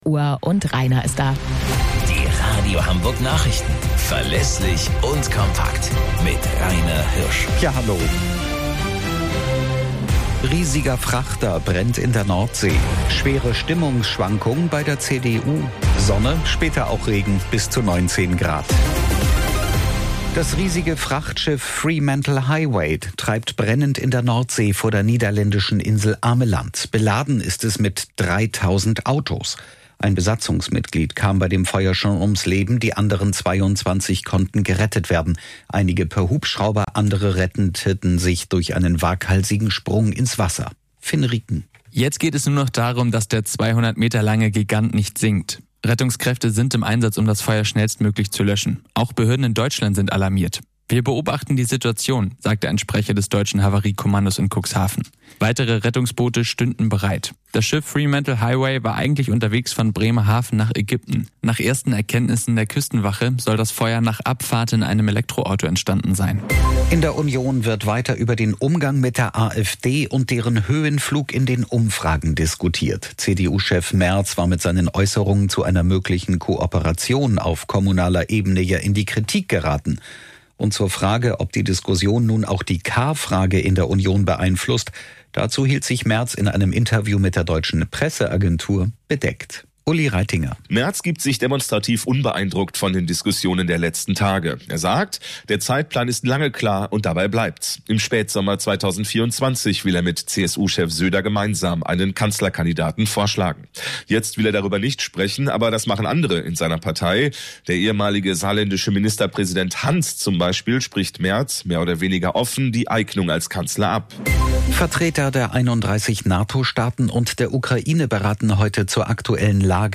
Radio Hamburg Nachrichten vom 26.07.2023 um 18 Uhr - 26.07.2023